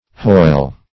hoyle - definition of hoyle - synonyms, pronunciation, spelling from Free Dictionary